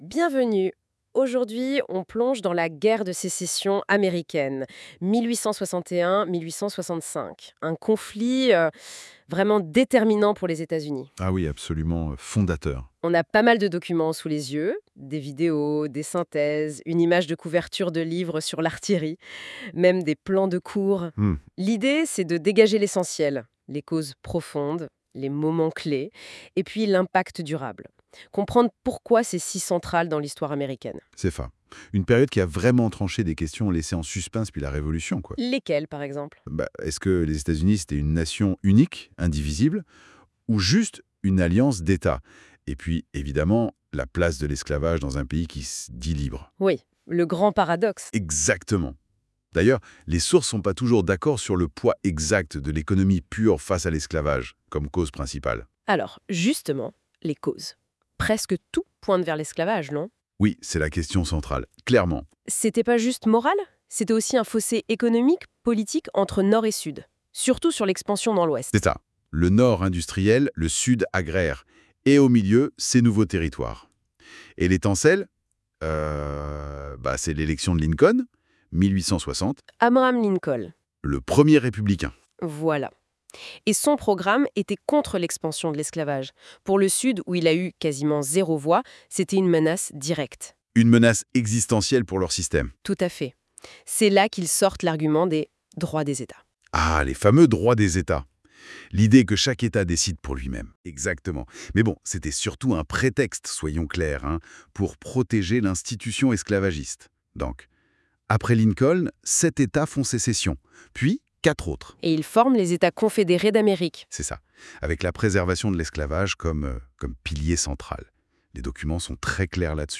Deux voix d’IA dialoguent à la manière d’un échange entre experts.